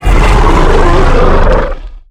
giant_aggressive_1.ogg